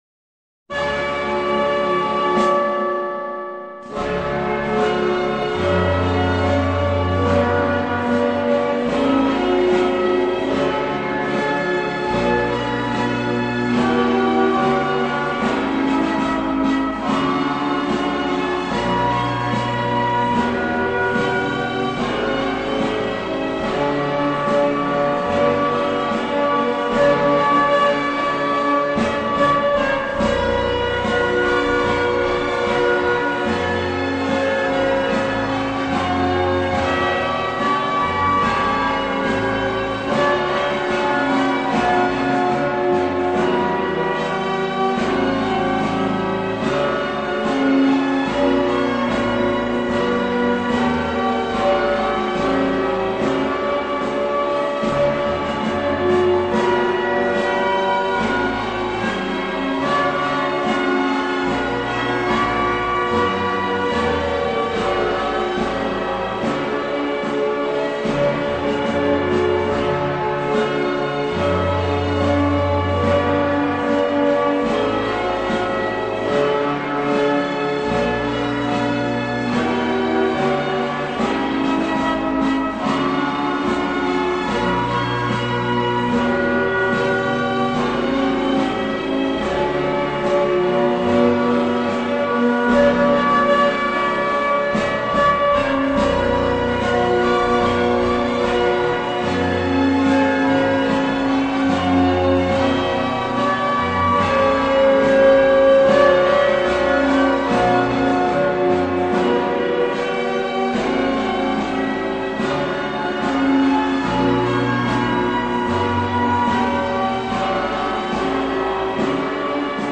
инструментальный